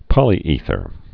(pŏlē-ēthər)